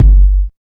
64 KICK.wav